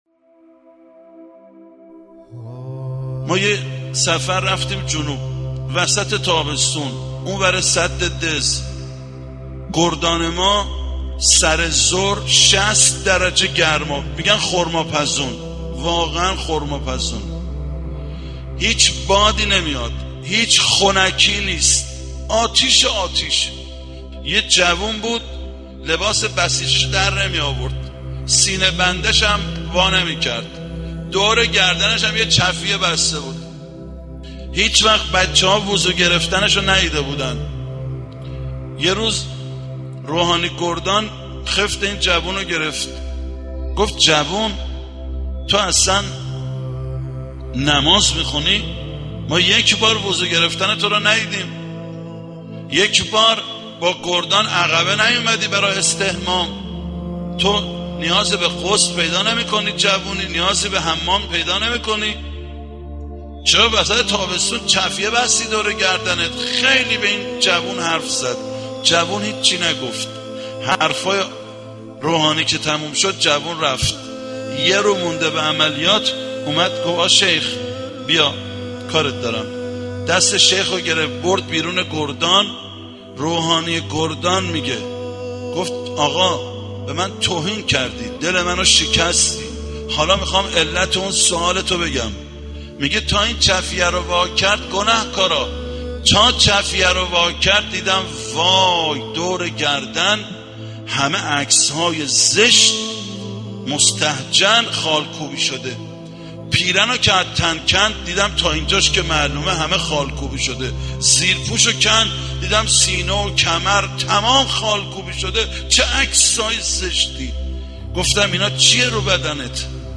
صوت سخنرانی